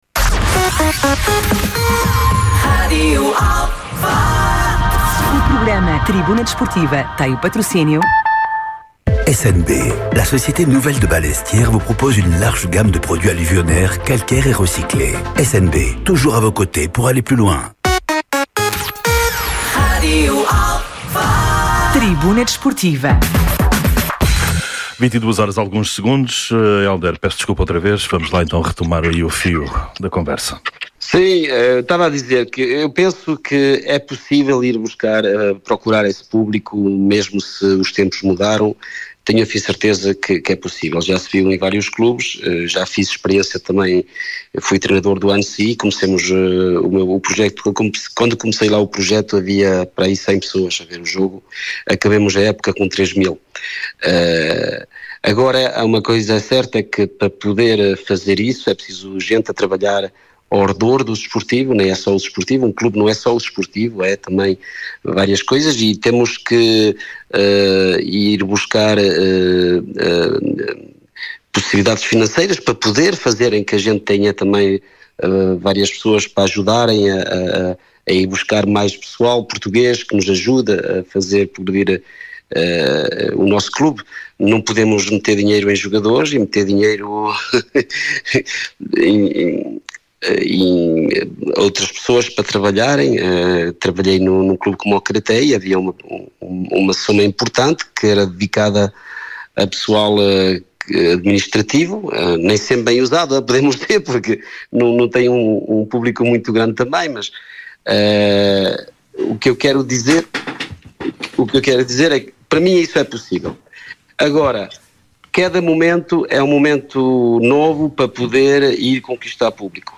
Atualidade Desportiva, Entrevistas, Comentários, Crónicas e Reportagens.
Tribuna Desportiva é um programa desportivo da Rádio Alfa às Segundas-feiras, entre as 21h e as 23h.